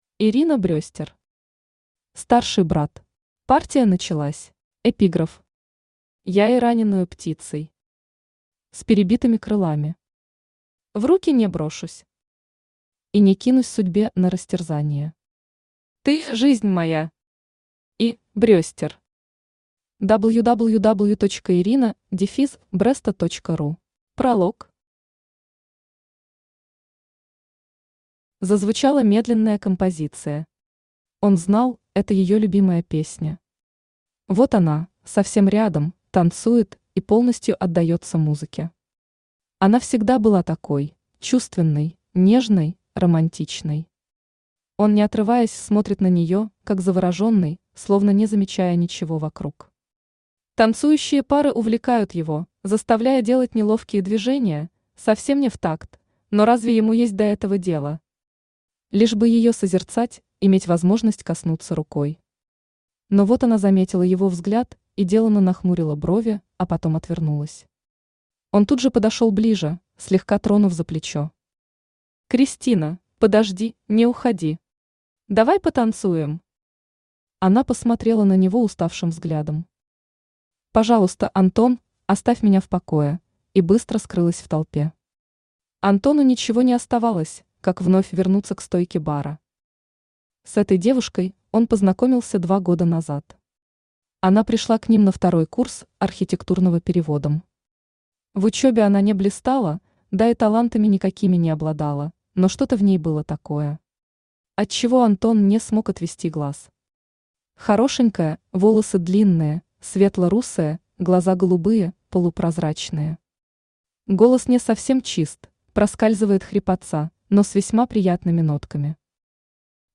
Аудиокнига Старший брат. Партия началась | Библиотека аудиокниг
Партия началась Автор Ирина Брестер Читает аудиокнигу Авточтец ЛитРес.